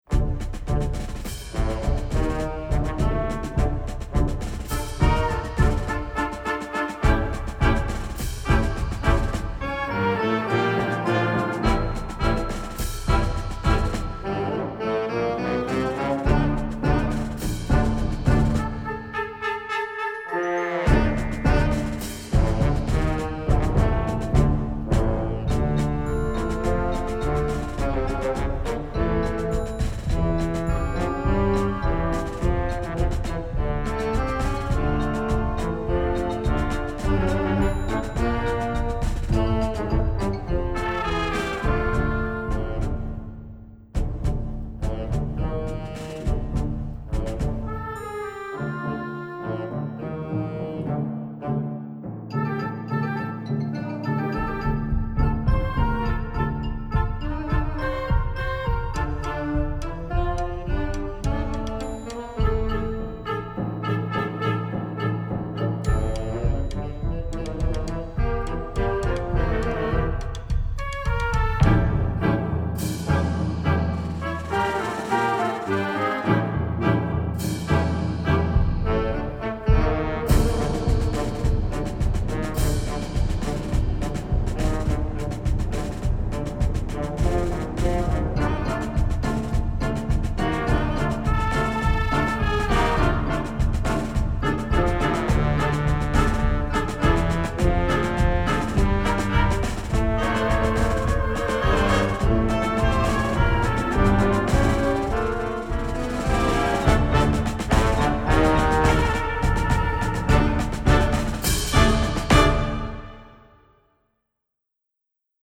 Voicing: Flex March